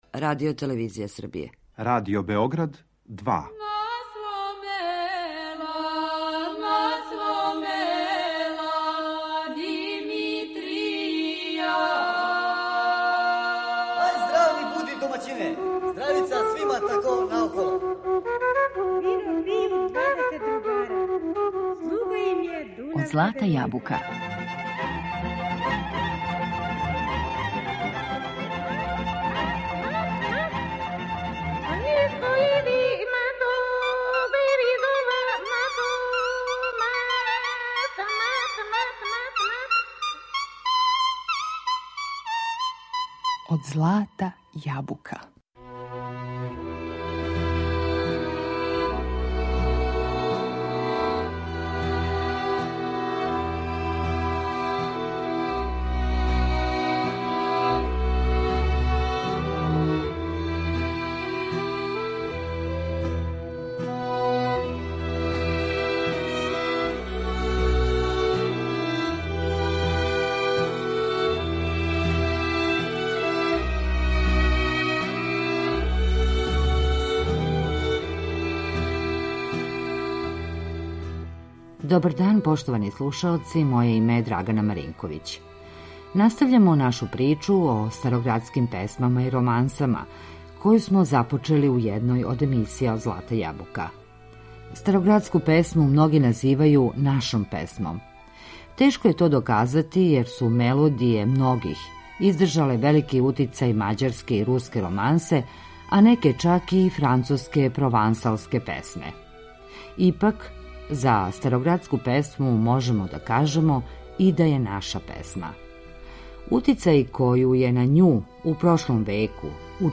Староградске песме и романсе (други део)
Утицај који је на њих у 19 веку имала европска музика донео је нашим мелодијама салонску ноту.